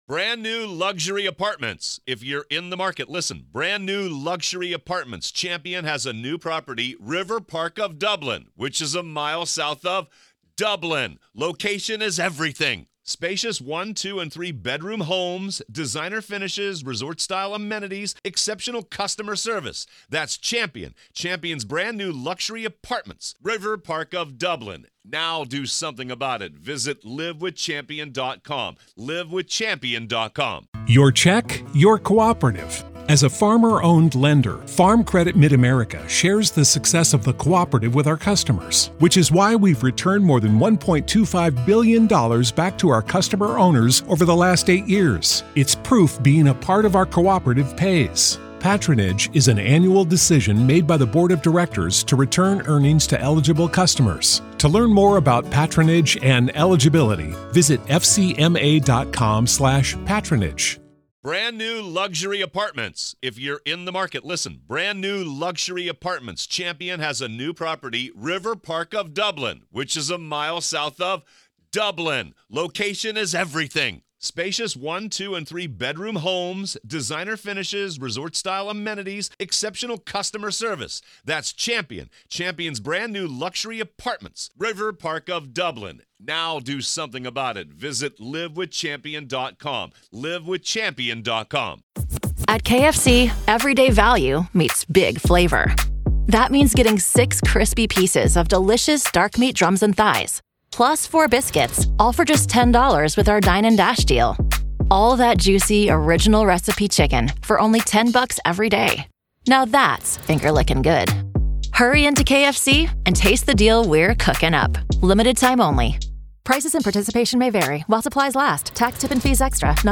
The conversation delved deeper into the legal and psychological aspects of the case.